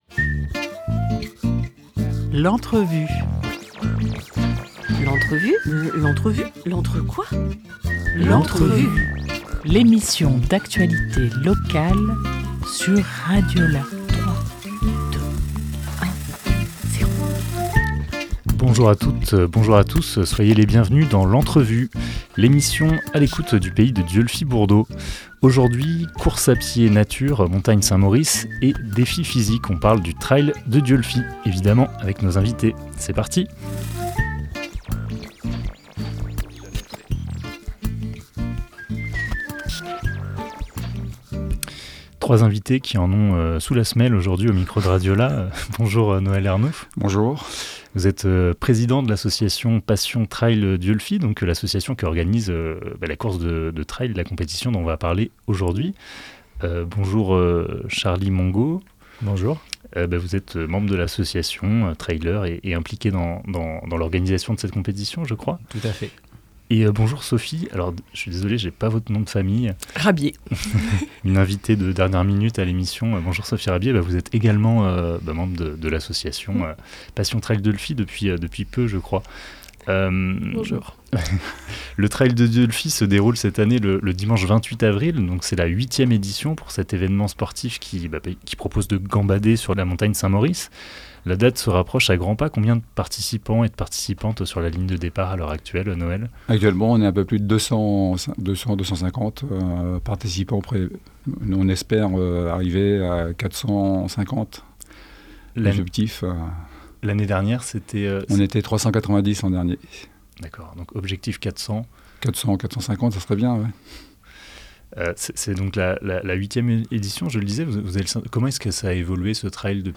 16 avril 2024 12:02 | Interview